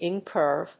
アクセント・音節ín・cùrve